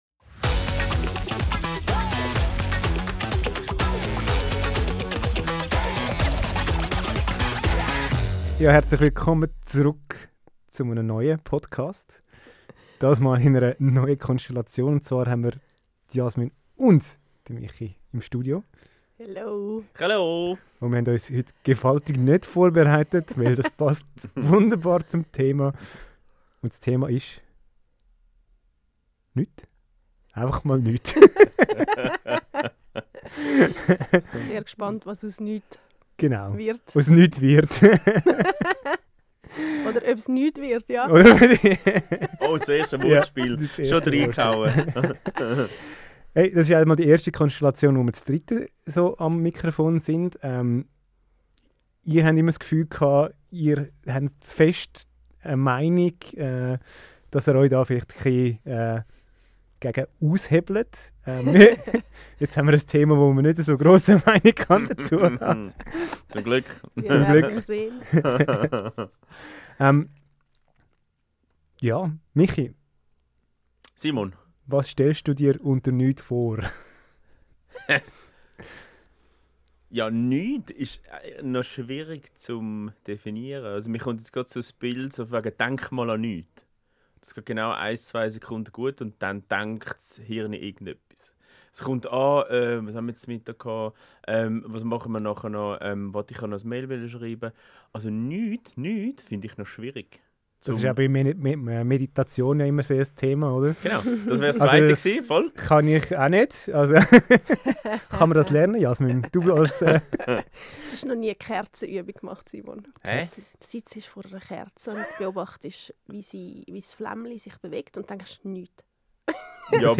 Wir teilen unsere eigenen Erfahrungen mit "Nichts" und laden dich ein, über dessen Rolle in deinem eigenen Leben nachzudenken. "Heute mal zu dritt - NIX" bietet eine unterhaltsame und tiefgründige Diskussion über ein Thema, das oft unterschätzt wird, aber dennoch von großer Bedeutung ist.